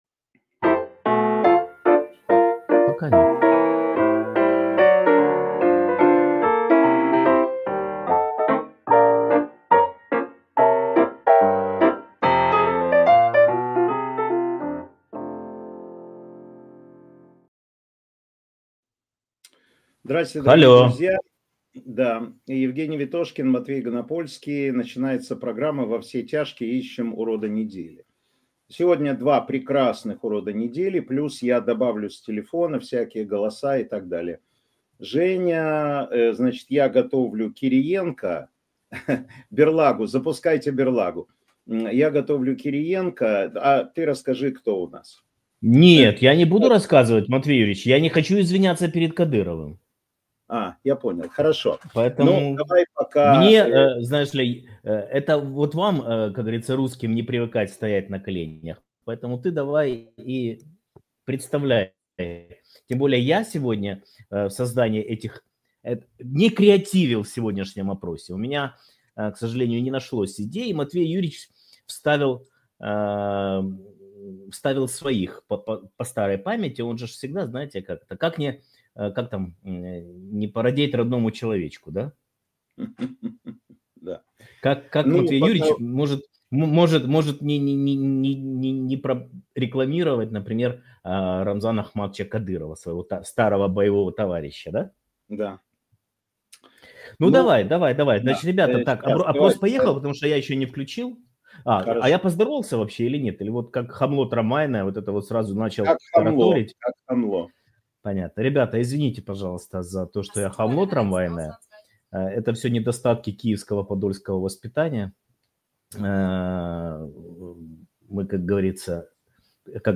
Эфир ведёт Матвей Ганапольский